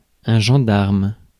Ääntäminen
Synonyymit hareng saur militaire furie mégère vairon pyrrhocore Ääntäminen Tuntematon aksentti: IPA: /ʒɑ̃.daʁm/ Haettu sana löytyi näillä lähdekielillä: ranska Käännöksiä ei löytynyt valitulle kohdekielelle.